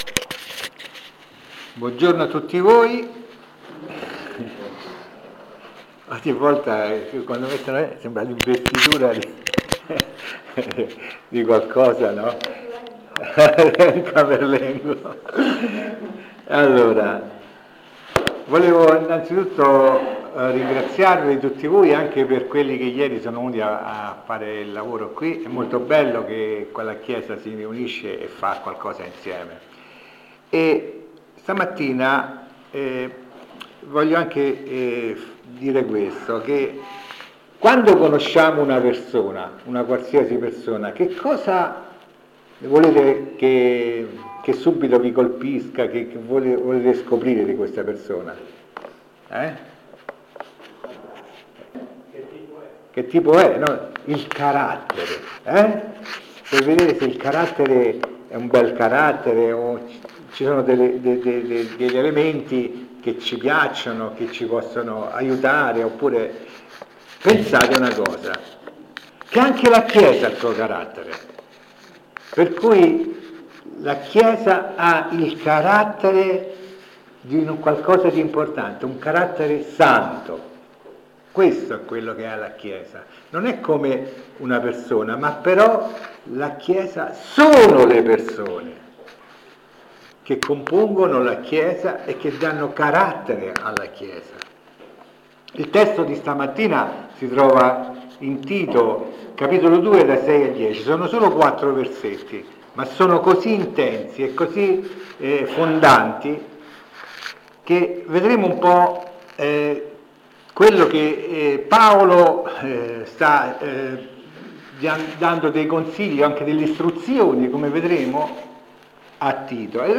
Insegnamenti biblici del 27/04/2025 sul passo di Tito 2:6-10.